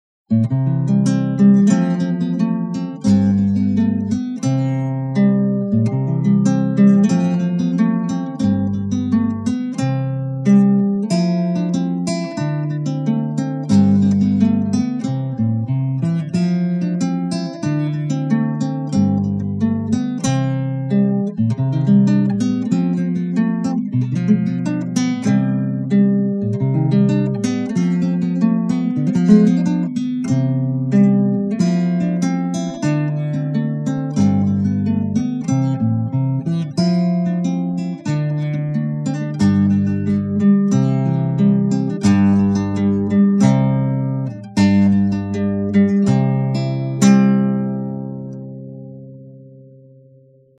до-мажор